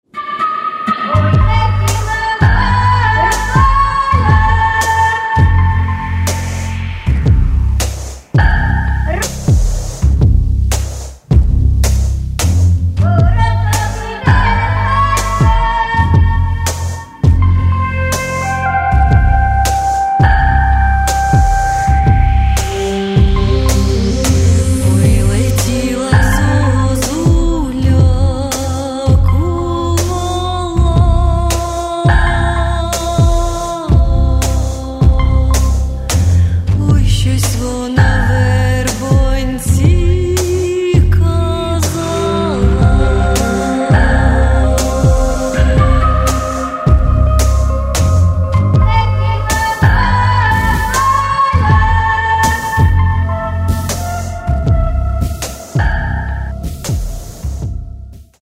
Каталог -> Поп (Легкая) -> Этно-поп